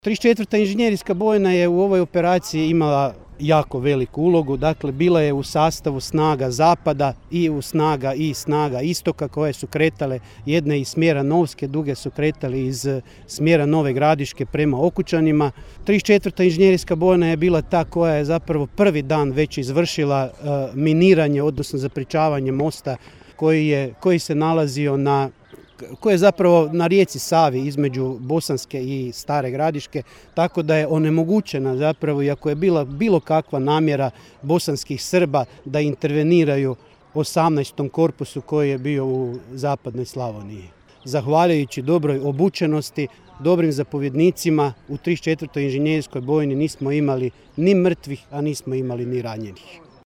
VRO Bljesak, obilježavanje u Čakovcu, 1.5.2022.